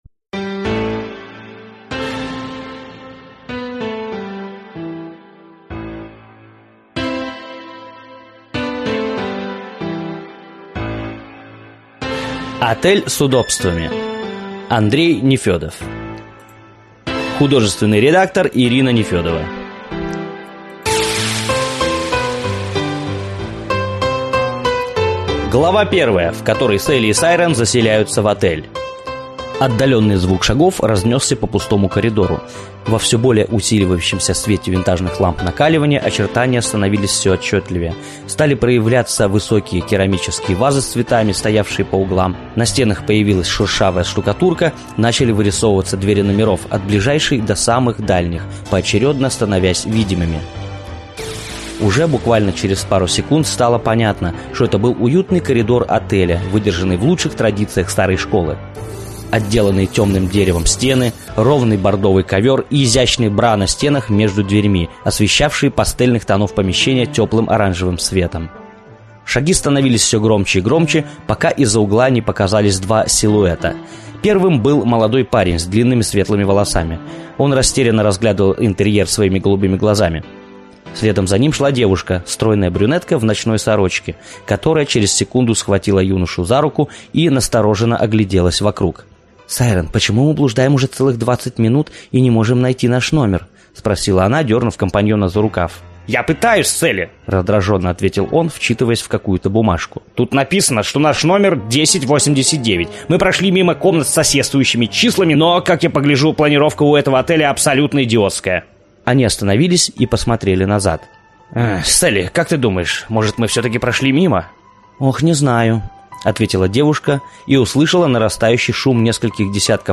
Аудиокнига Отель с Удобствами | Библиотека аудиокниг